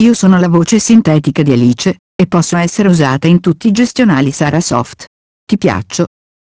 E' la pronuncia di messaggi con voce umana sintetica, ad esempio "Benvenuto e buon lavoro" all'apertura del programma, "Confermi la cancellazione ?" in caso di richiesta di cancellazione di un dato da un archivio, eccetera.
testo pronunciato con la voce "Alice"
esempio-alice.wav